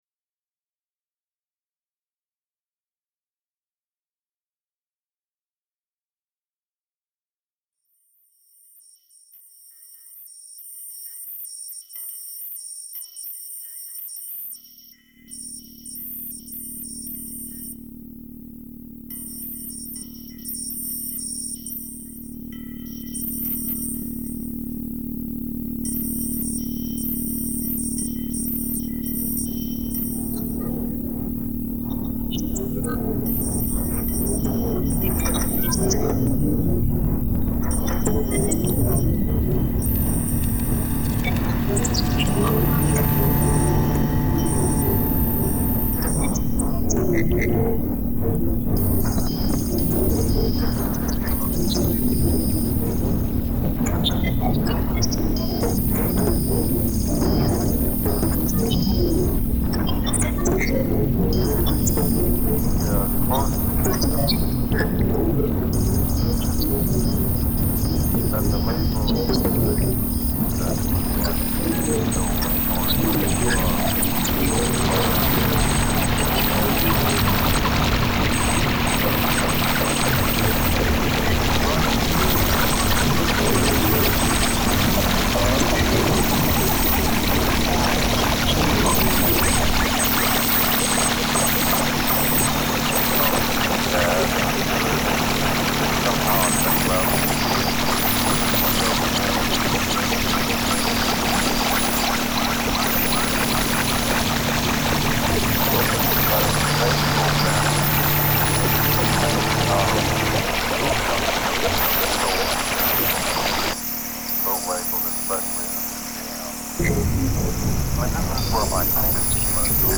resulting in very abstract and experimental tracks